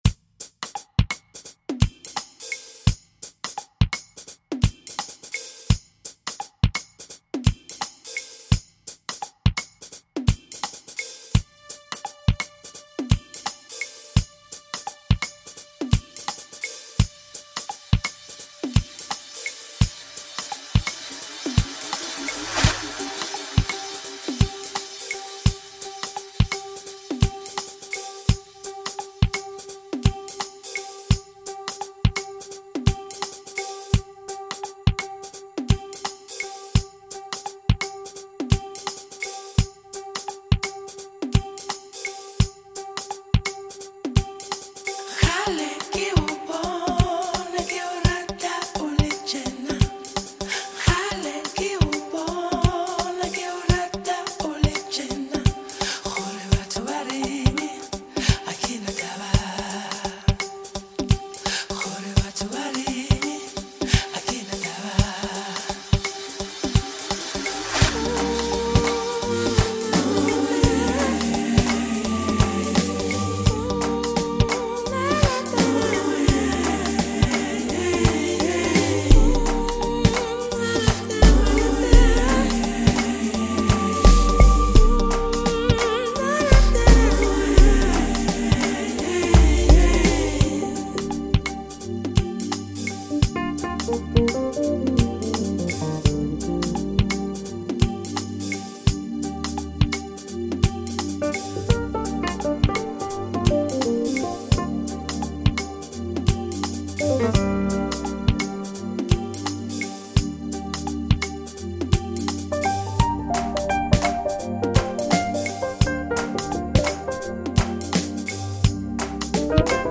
soul lifting voice
keytar